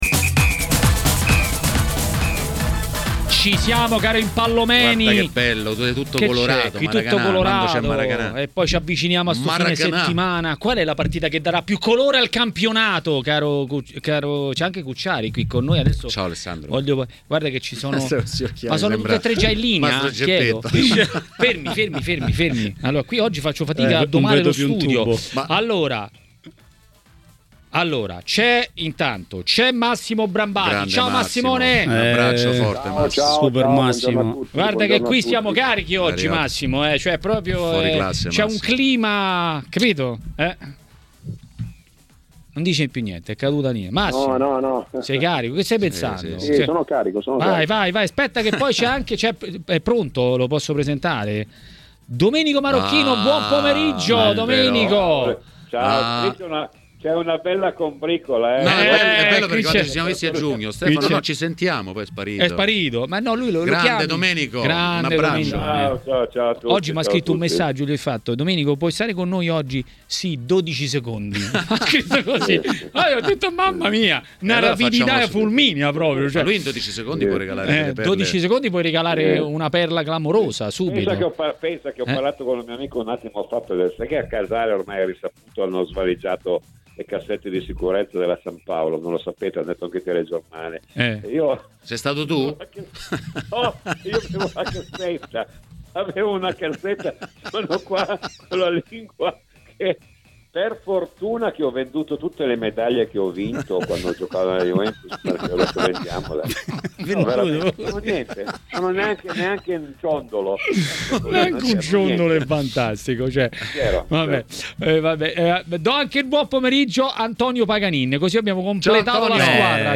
A commentare l'ultimo turno di Serie A a TMW Radio, durante Maracanà, è stato Antonio Paganin, ex calciatore.